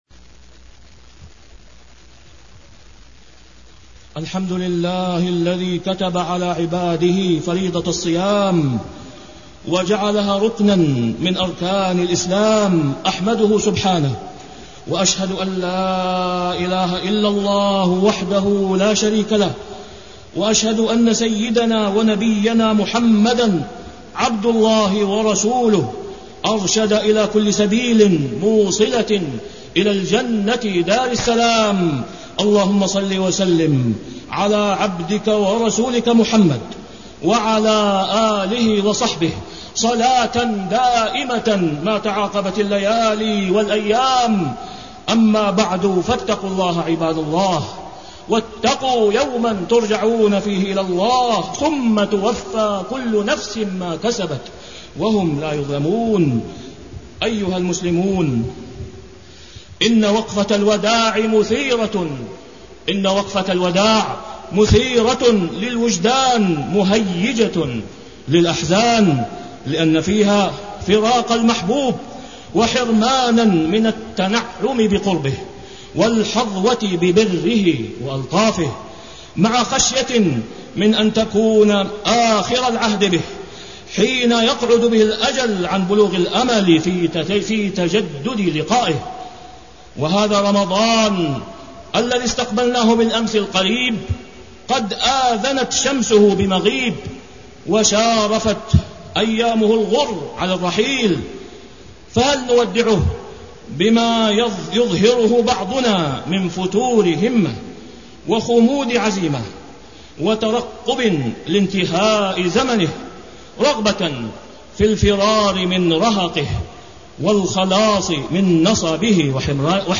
تاريخ النشر ٢٦ رمضان ١٤٢٩ هـ المكان: المسجد الحرام الشيخ: فضيلة الشيخ د. أسامة بن عبدالله خياط فضيلة الشيخ د. أسامة بن عبدالله خياط كيف سيكون وداعك للحبيب رمضان